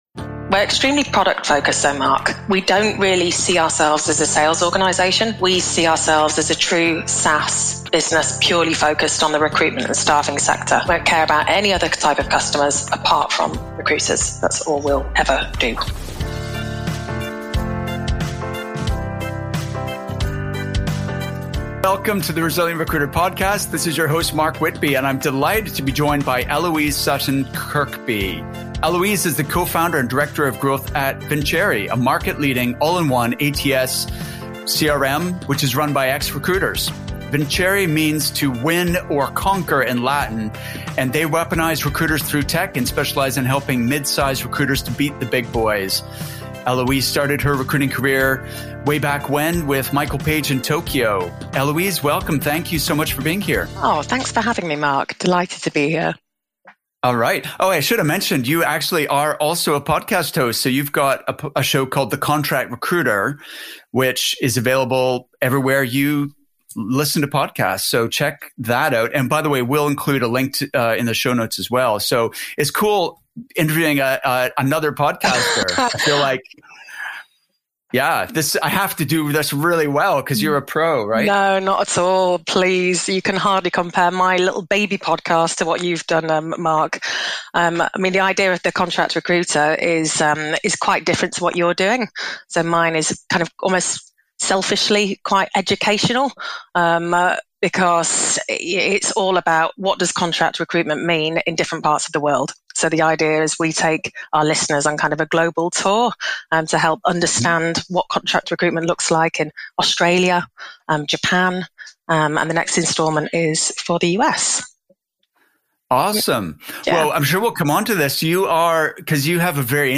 In either case, you’re in for a treat as I interview